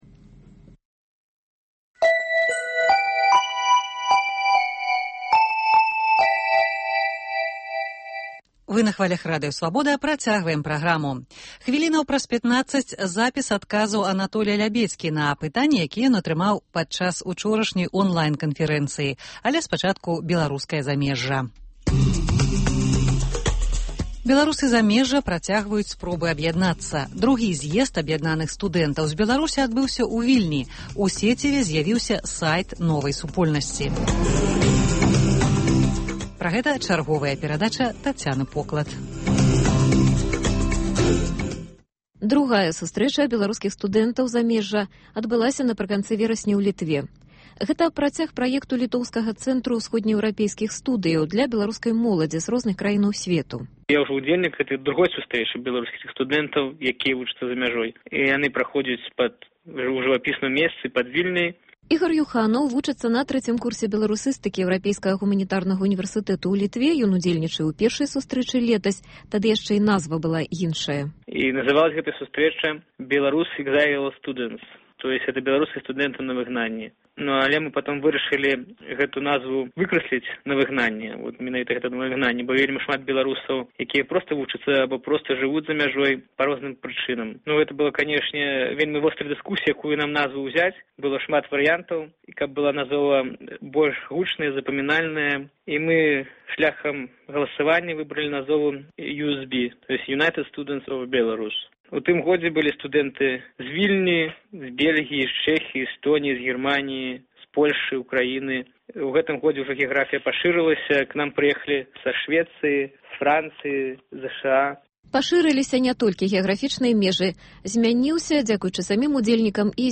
Беларуская дыяспара ў глябалізаваным сьвеце. Размова пра беларускія студэнцкія таварыствы за мяжой.